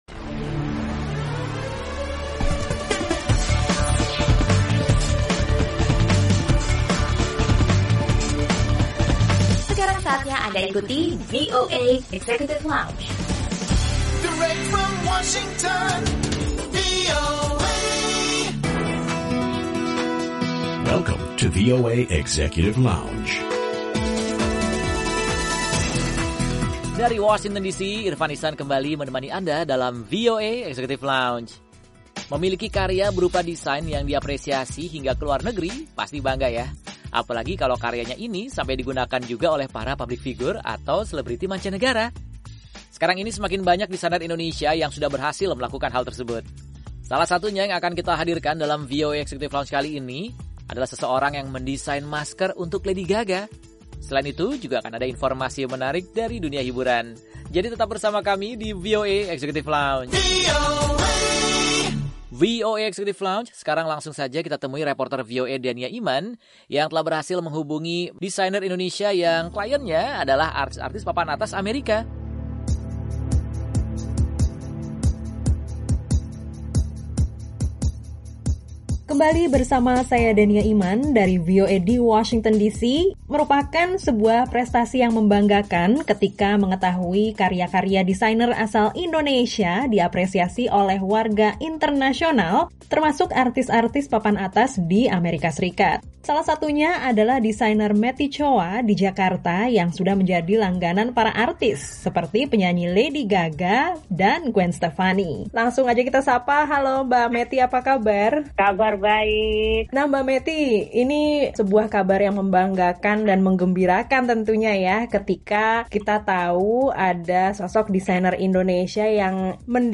Obrolan bersama seorang desainer Indonesia